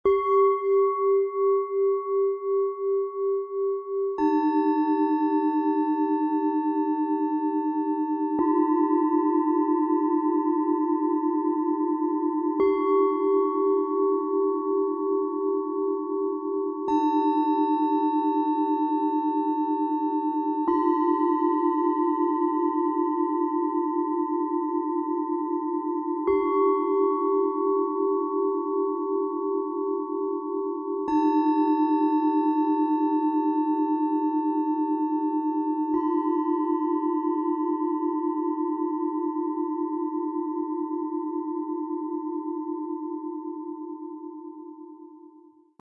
Geerdete Lebensfreude & heilsame Weisheit: Stabil im Gefühl, leicht im Sein - Set aus 3 Planetenschalen, Ø 11,7 -13,1 cm, 0,77 kg
Es entsteht ein Klangraum, in dem innere Führung, Vertrauen und Lebensfreude wachsen dürfen.
Ein wohltönender Klöppel ist im Set enthalten – perfekt auf die Schalen abgestimmt.
Tiefster Ton: Tageston
Bengalen-Schale, Matt
Mittlerer Ton: Eros
Bihar-Schale, Matt
Höchster Ton: Chiron
MaterialBronze